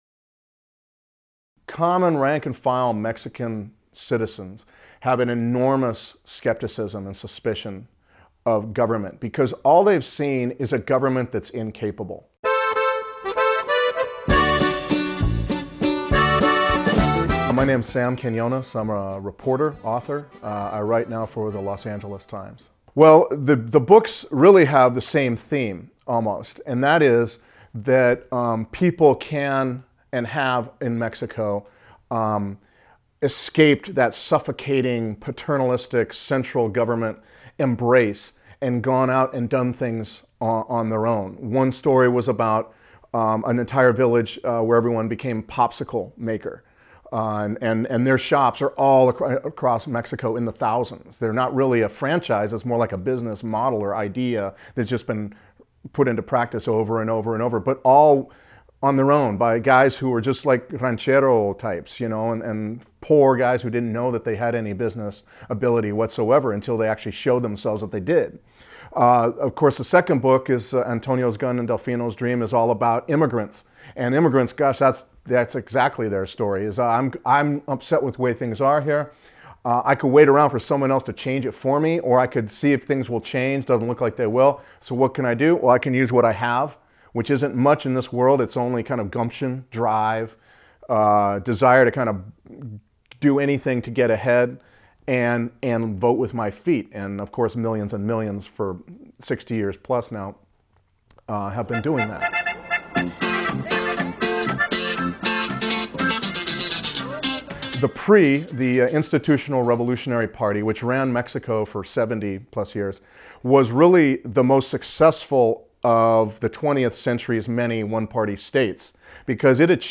Another Mexico: A Conversation